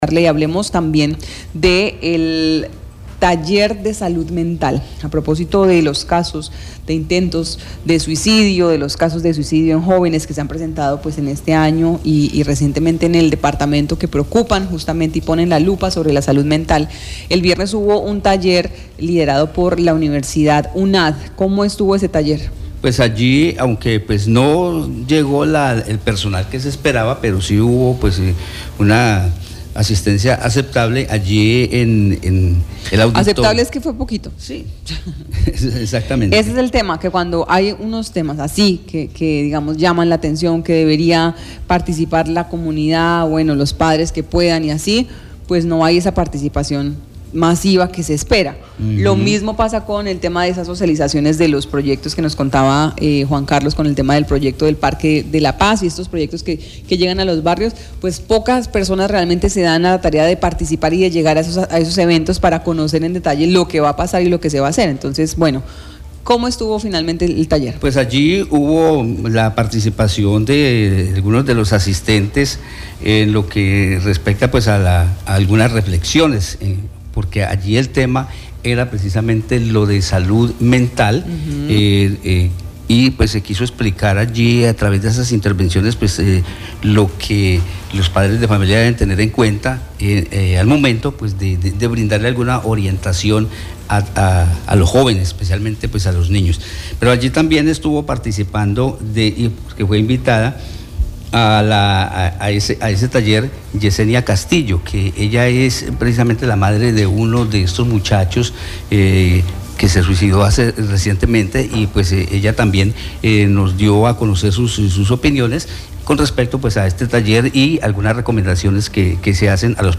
Con una aceptable asistencia se realizó el taller motivacional “Una Puerta a la Vida”, organizado y dirigido por la Universidad Abierta y a Distancia UNAD.
Enel auditorio hubo la intervención de varios profesionales de la Universidad, quienes a través de actividades de reflexión expusieron primero que todo el valor de la vida.